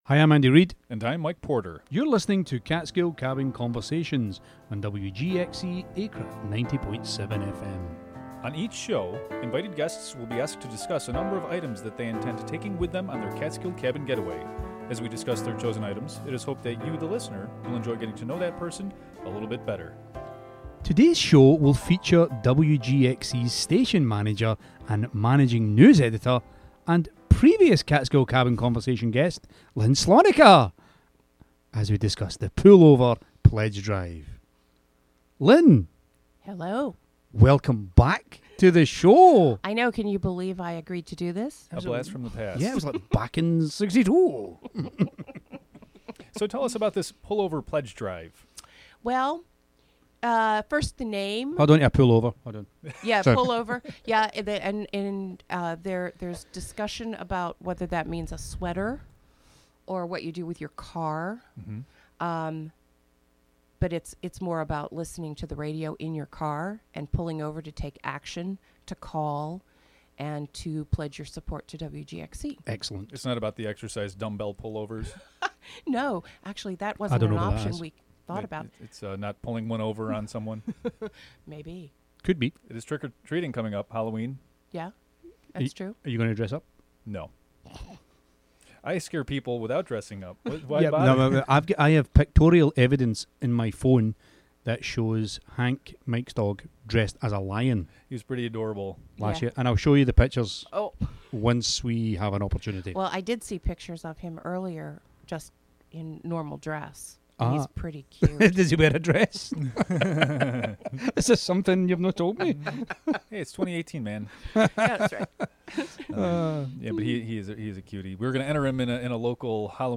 Catskill Cabin Getaway